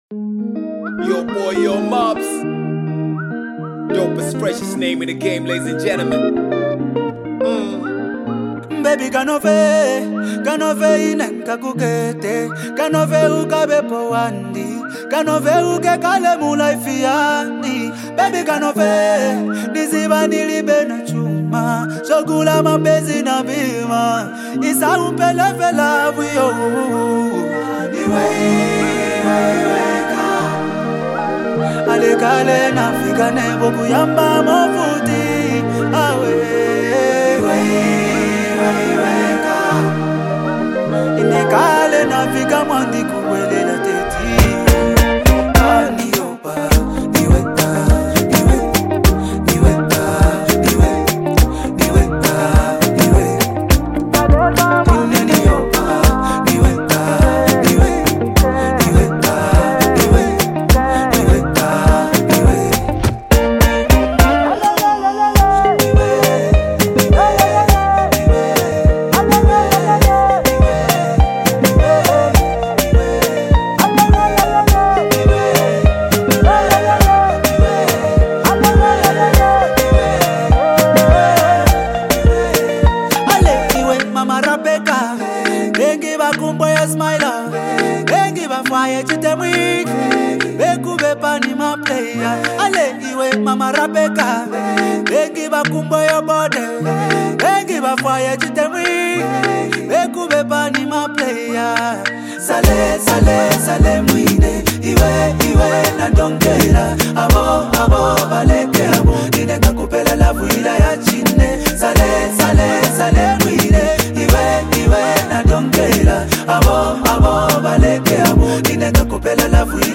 blending deep instrumentals with a smooth, uplifting melody.